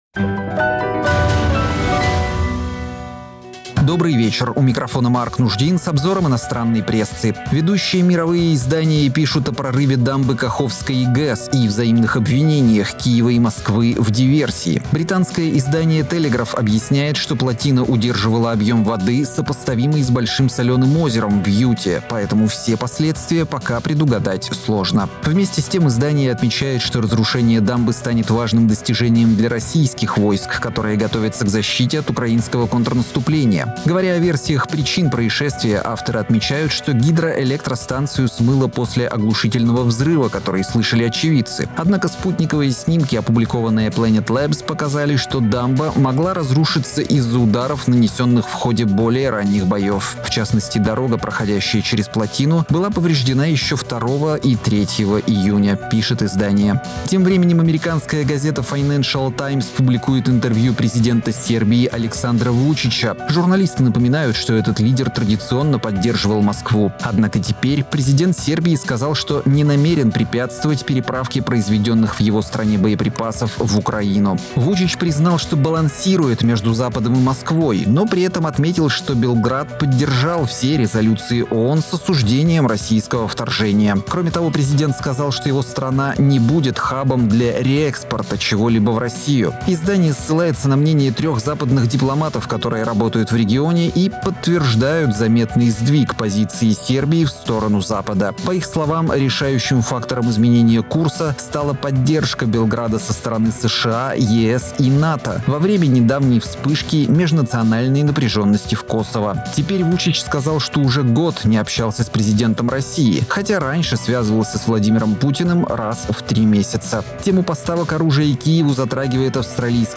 Обзор инопрессы 06.06.2023